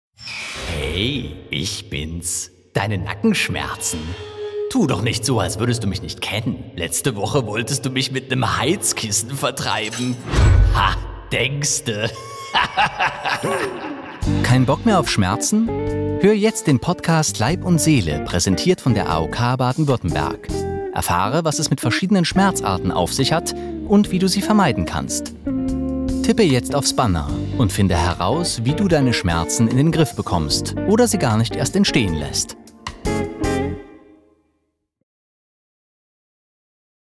dunkel, sonor, souverän, markant, sehr variabel
Mittel minus (25-45)
Commercial (Werbung)